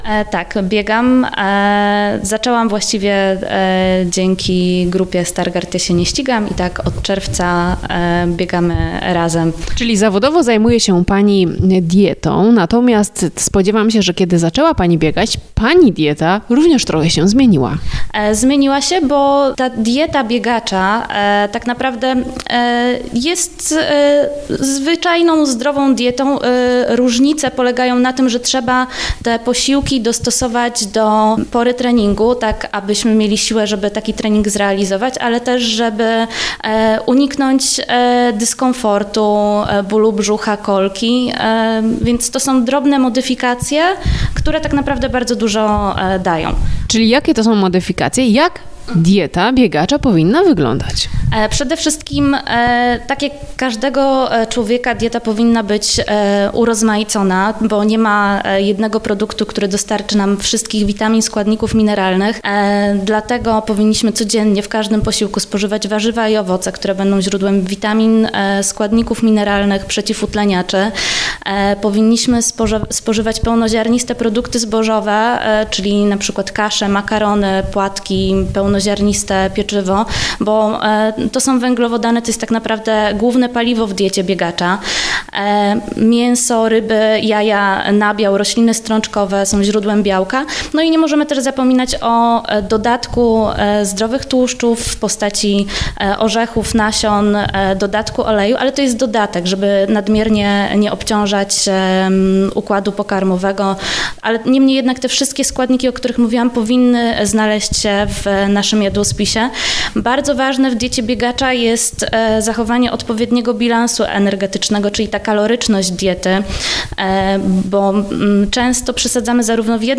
w rozmowie z dietetyczką